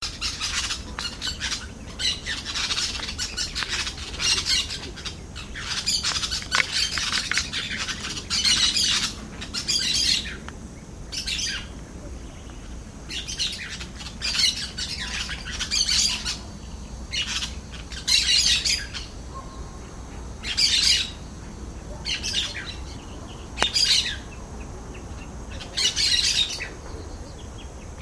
Catita Chirirí (Brotogeris chiriri)
Al menos 12 ejemplares
Nombre en inglés: Yellow-chevroned Parakeet
Localidad o área protegida: Reserva Ecológica Vicente López
Condición: Silvestre
Certeza: Fotografiada, Vocalización Grabada